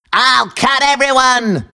Worms speechbanks
Watchthis.wav